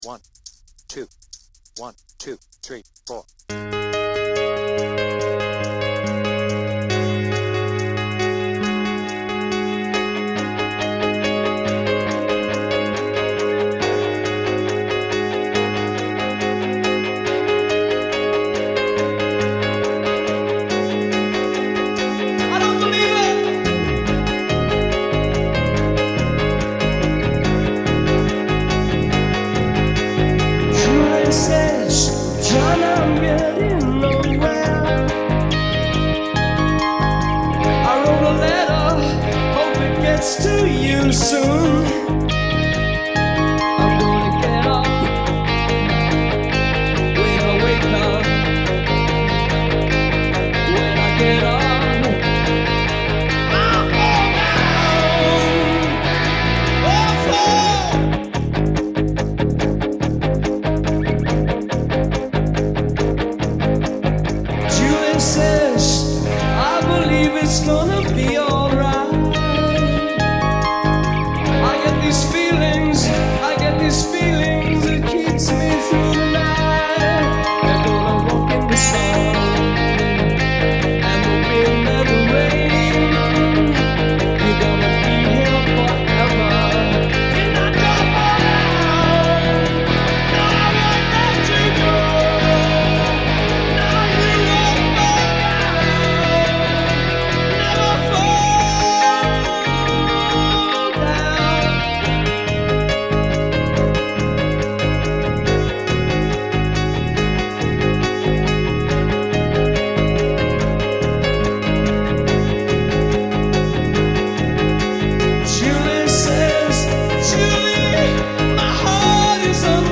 BPM : 141
Tuning : Eb
With vocals
Based on the 1982 Hammersmith Palais live version